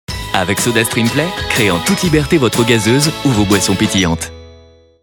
VOIX OFF SIGNATURE
7. SODASTREAM jeune adulte